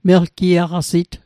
Pronunciation Guide: mel·ki·a·hga·sit Translation: He/she is muscular